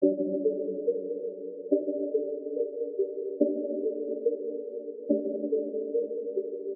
黑暗环境下的钟声 142BPM
使用的音阶是G小调
Tag: 142 bpm Trap Loops Bells Loops 1.14 MB wav Key : Gm FL Studio